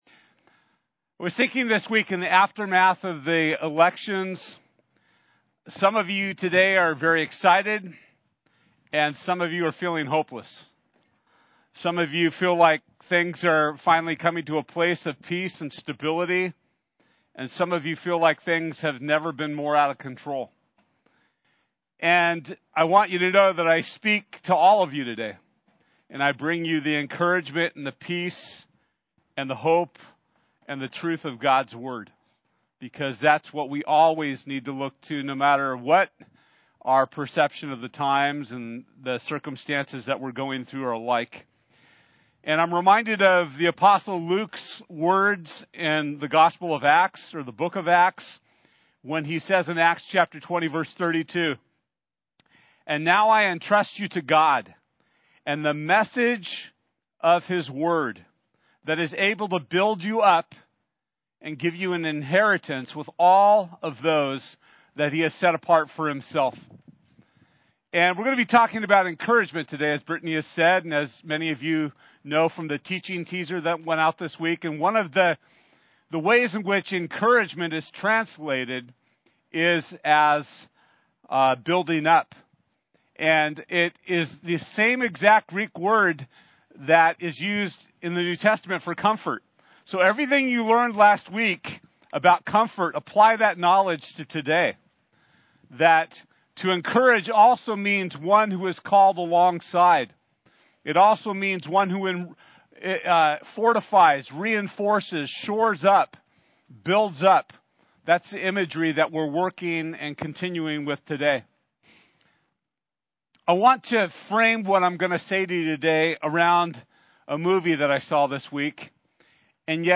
One Another Service Type: Sunday This week we’ll be talking about what it means to Encourage One Another!